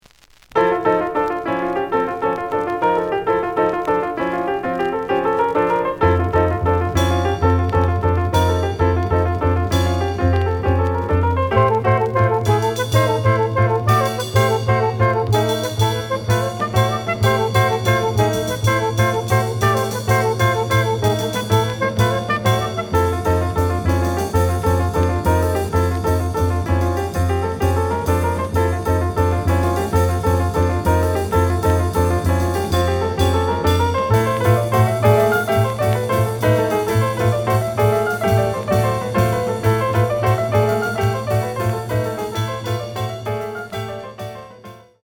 The audio sample is recorded from the actual item.
●Genre: Cool Jazz
Some click noise on later half of B side due to scratches.